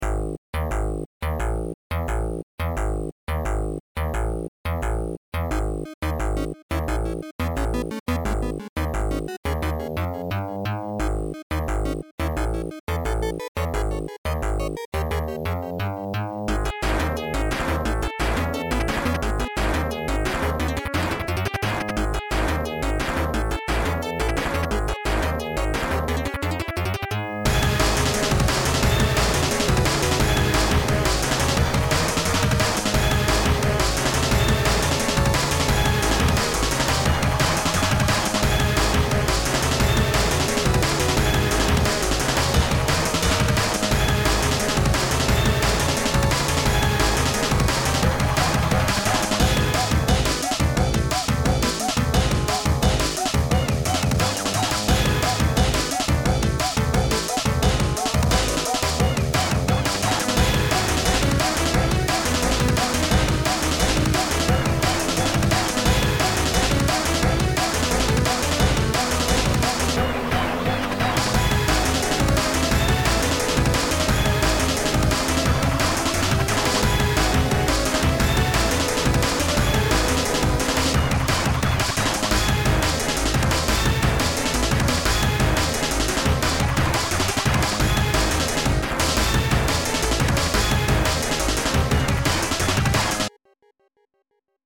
chase theme